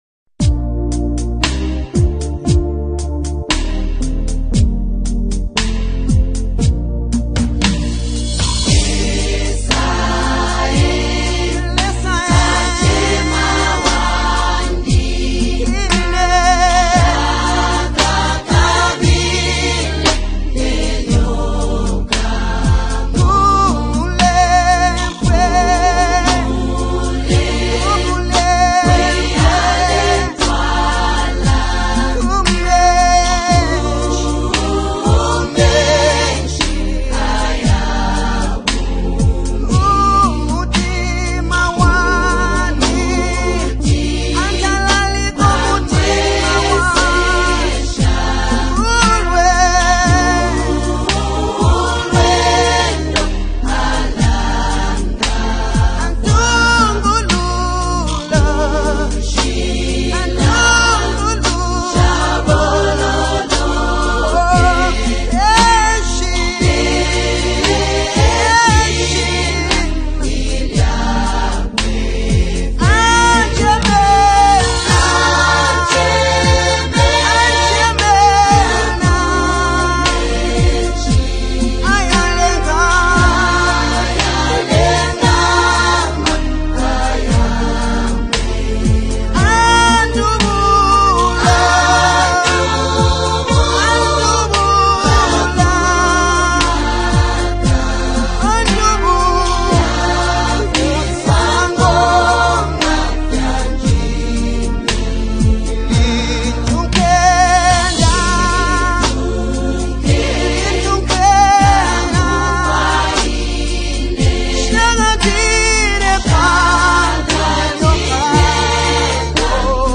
A Soul-Stirring Gospel Experience